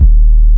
TM88 DarkDeep808.wav